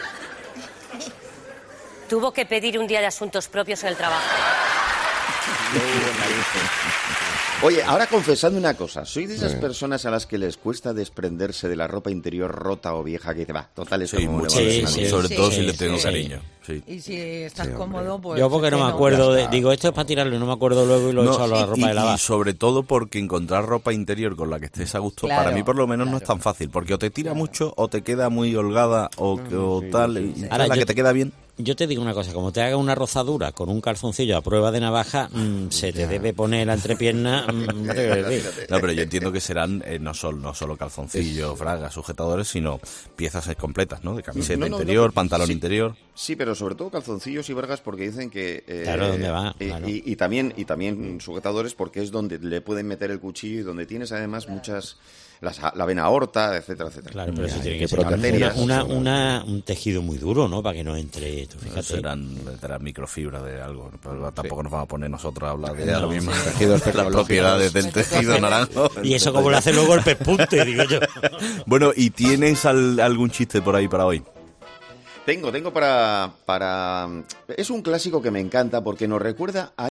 Sin embargo, el resto de colaboradores presentes en el plató se han sentido identificados con sus palabras y han confirmado que también les pasa, pero que, a pesar de ello, se debe renovar cada determinado tiempo.